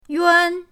yuan1.mp3